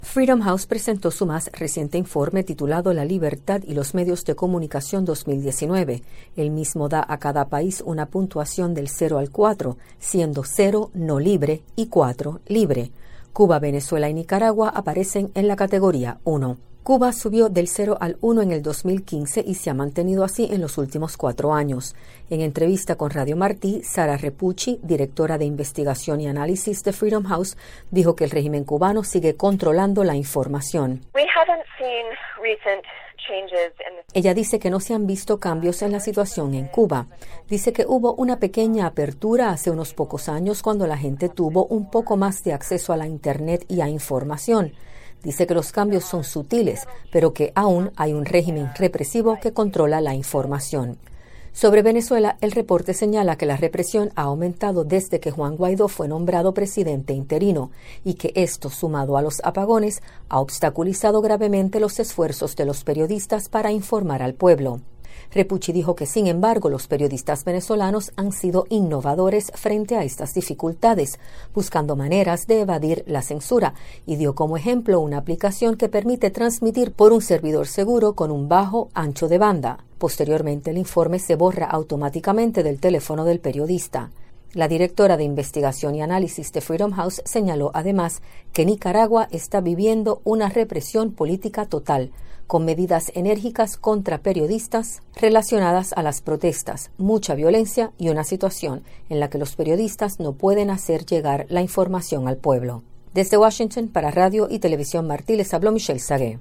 En entrevista con Radio Televisión Martí